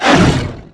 hellhound_dmg.wav